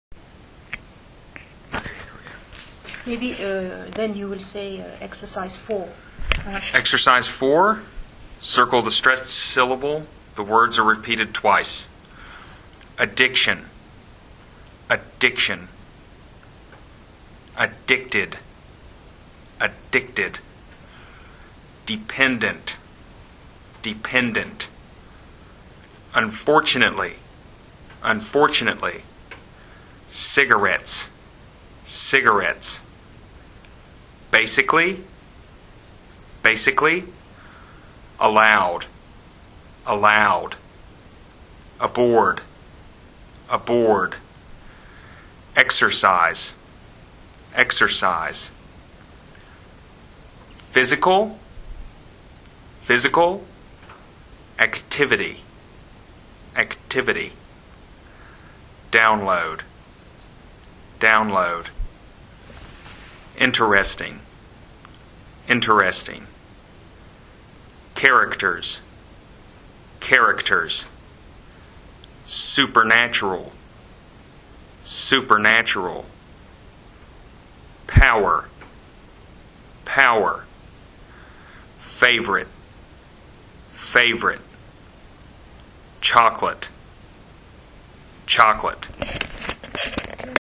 Quelques exercices phonologiques ont été crée en enregistrant l’assistant d’anglais avec la fonction dictaphone du baladeur numérique :
ex_4_circle_the_stress_syllable-894.mp3